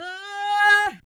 44 RSS-VOX.wav